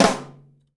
009_flam.wav